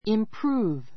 improve 中 A2 imprúːv イン プ ル ー ヴ 動詞 よりよくする , 改良する, 改善する; よくなる , 進歩する improve one's life [health] improve one's life [health] 生活を改善する[健康を増進する] His health is improving.